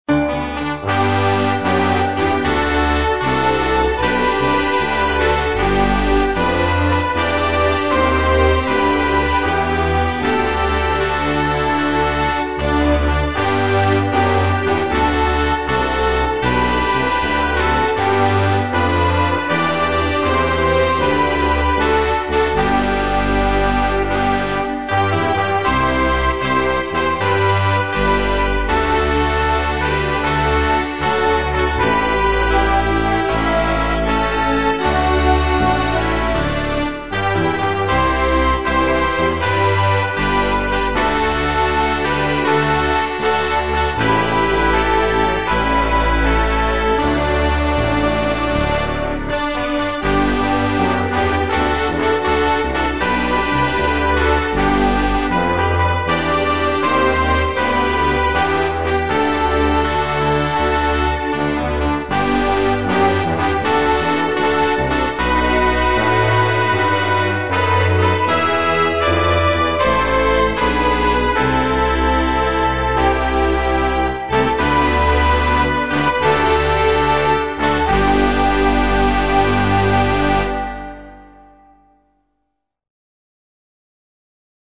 Inno nazionale